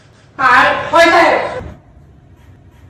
Thể loại: Câu nói Viral Việt Nam